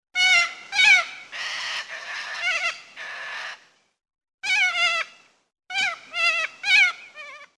Попугай какаду
sulfur-crested-cockatoo.mp3